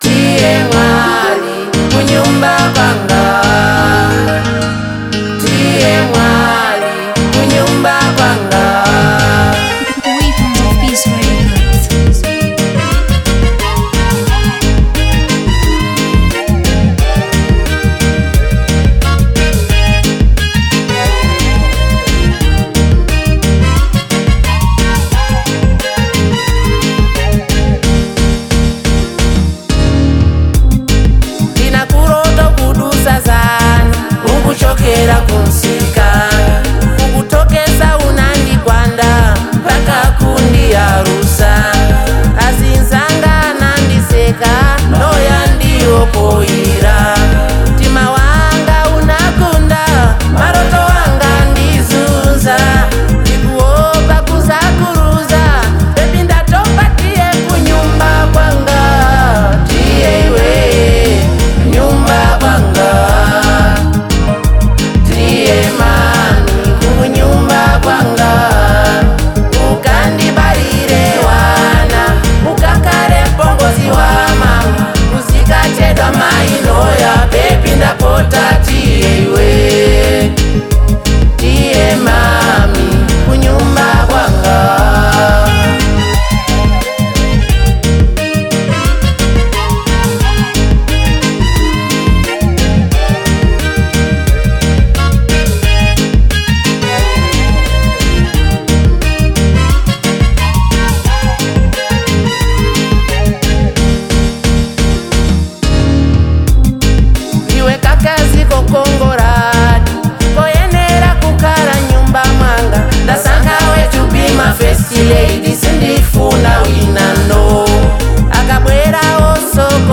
Malawian Afro • 2025-07-18